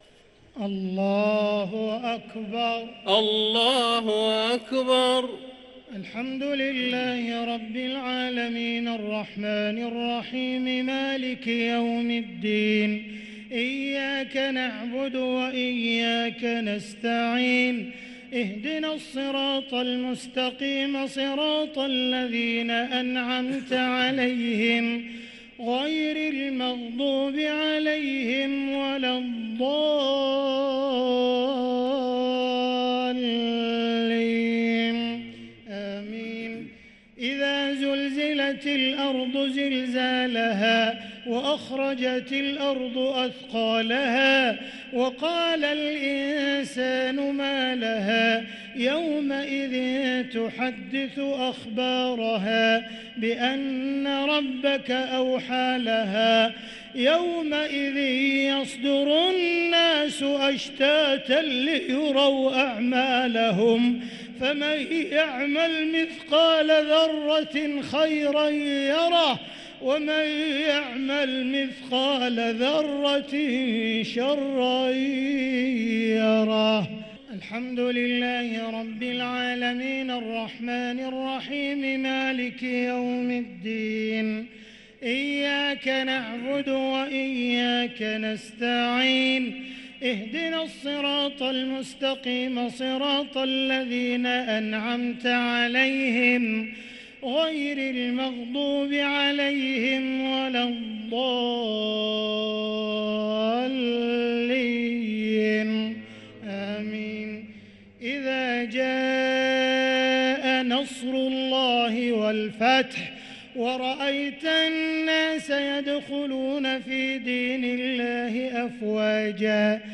صلاة التراويح ليلة 10 رمضان 1444 للقارئ عبدالرحمن السديس - الشفع والوتر - صلاة التراويح